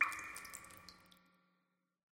amb_waterdrip_single_02.mp3